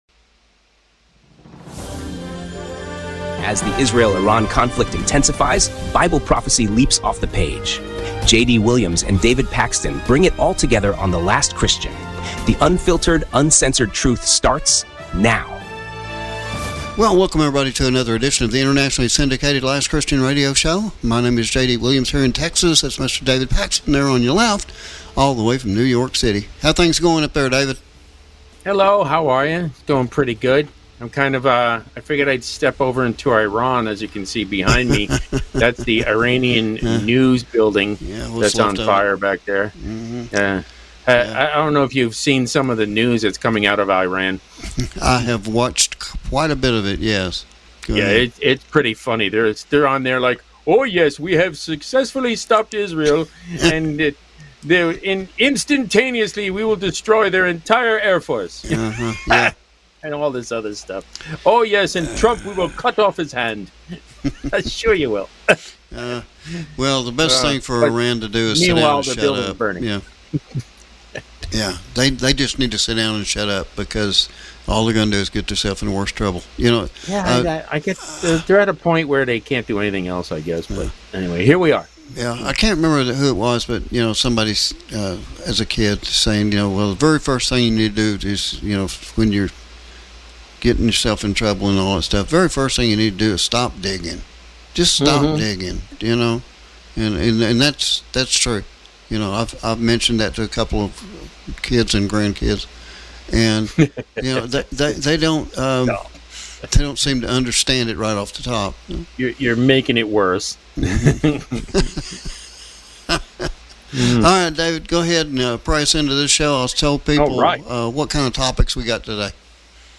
In this urgent broadcast, we connect current events to Scripture—Ezekiel 38–39, Isaiah 17, and Matthew 24—offering clarity, biblical truth, and hope in the midst of global